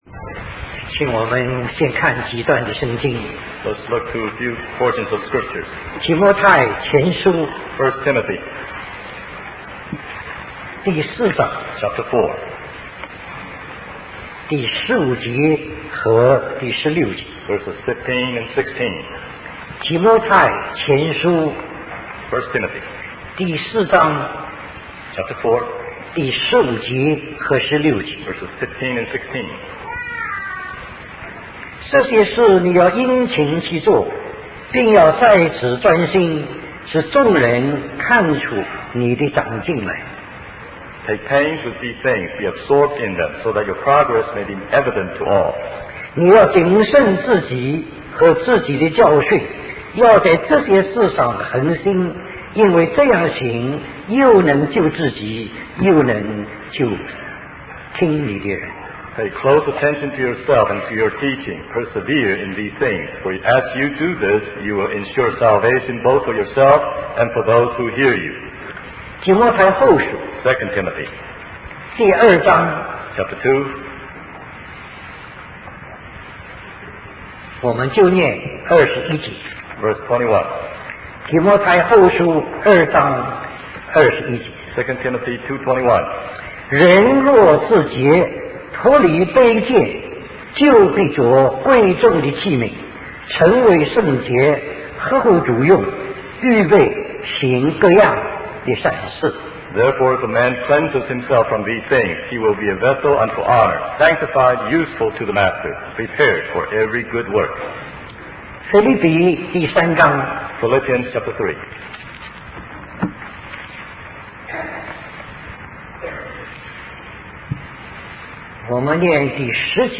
In this sermon, the speaker emphasizes the importance of learning to serve in the body of Christ. He highlights the need for believers to be connected to one another and to be trained together. The speaker also discusses the disciples' lack of understanding and obedience to the Lord's commands, using Peter as an example.